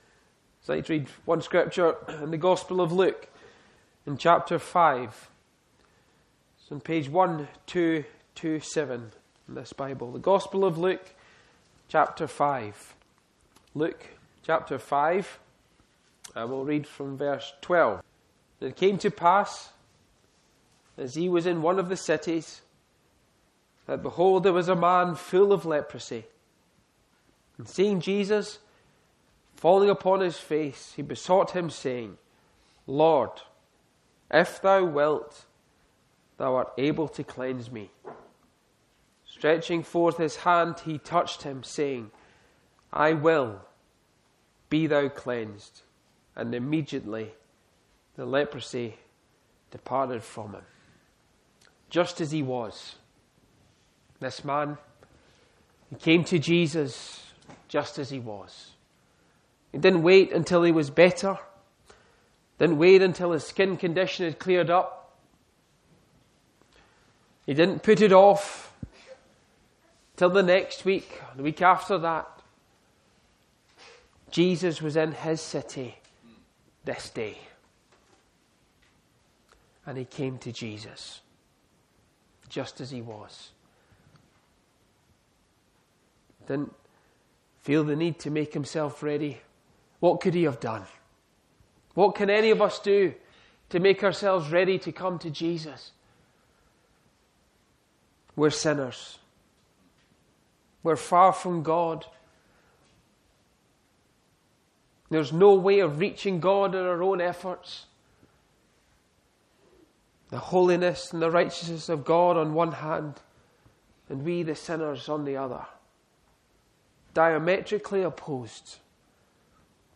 A Gospel preaching from Luke 5 on Jesus cleansing the leper—revealing His compassion, authority, and willingness to heal the leper.